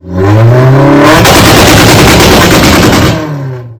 Spatial White Car Rev 3 Decibels.. Sound Button - Free Download & Play